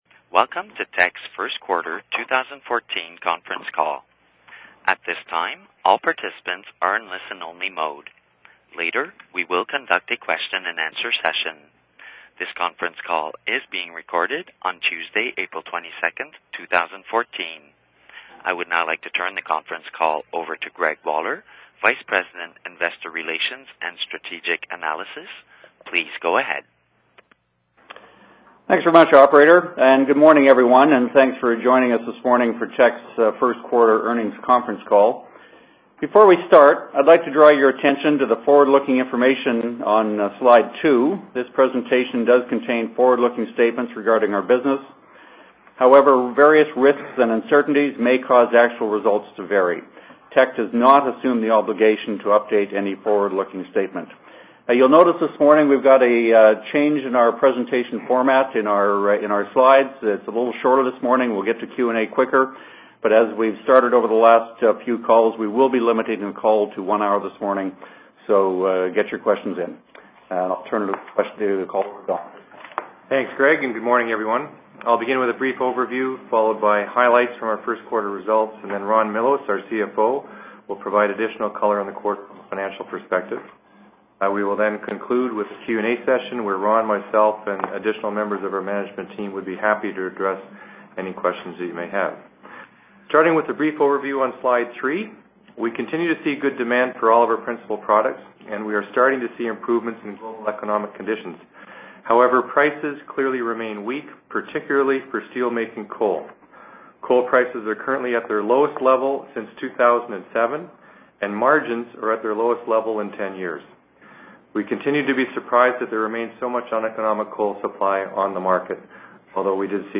q1-2014-conference-call-audio.mp3